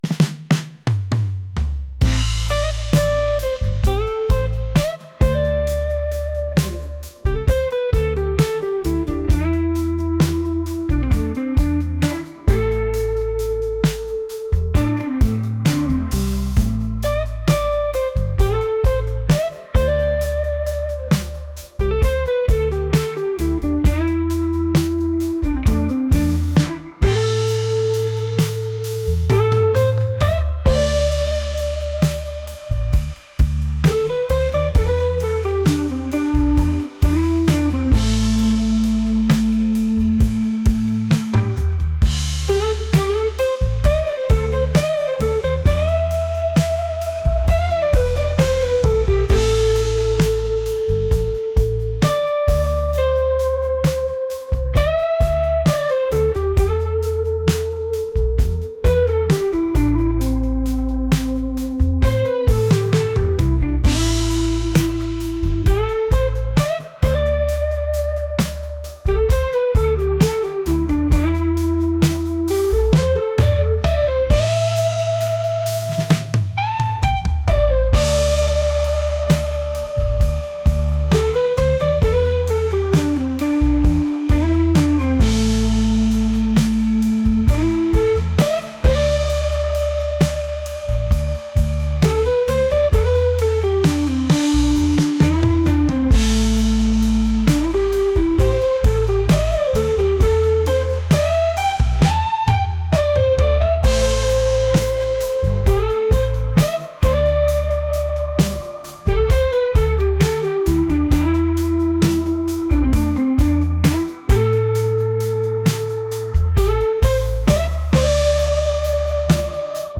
soul & rnb | retro | pop